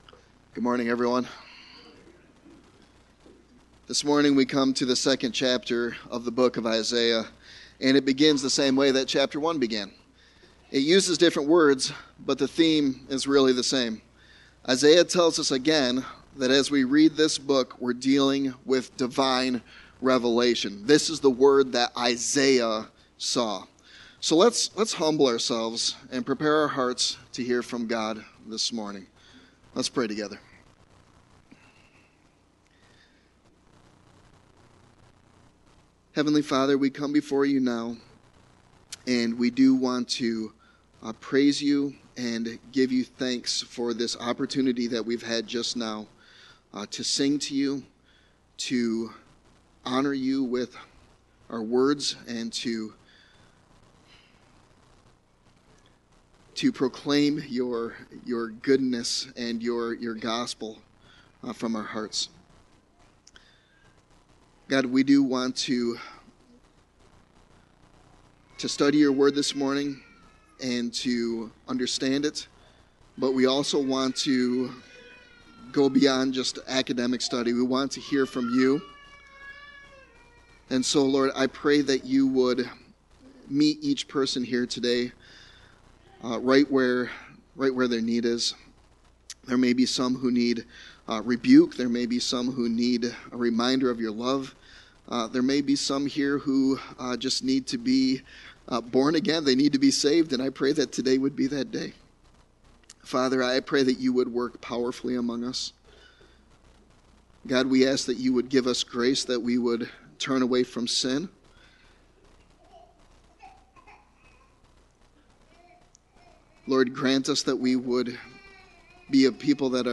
2024 Sermon Text: Isaiah 2:1-22 Teacher